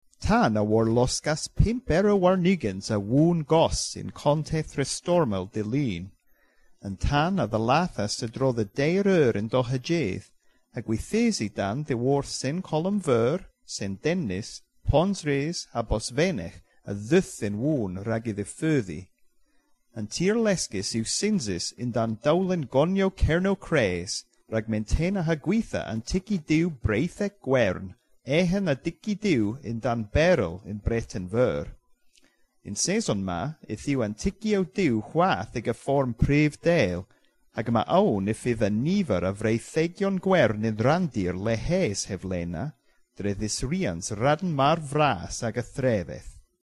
Here’s a recording of part of a news report in a mystery language.